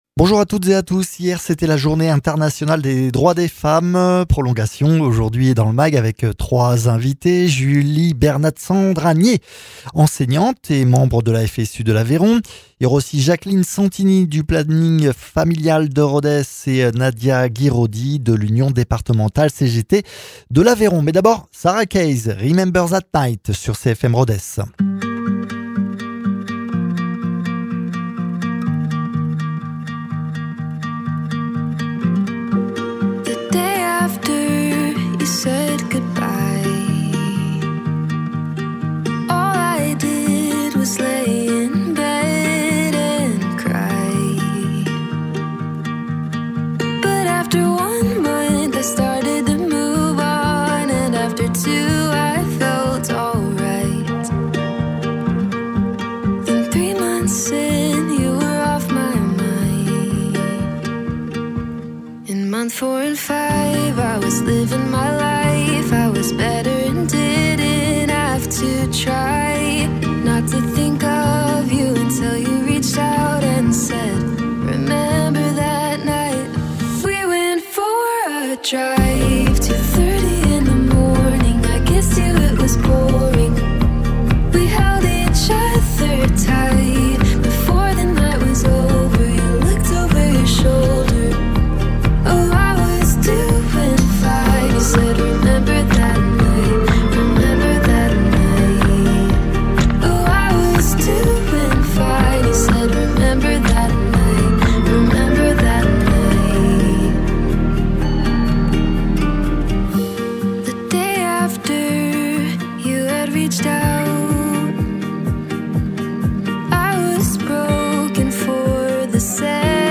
Le Mag Grand Rodez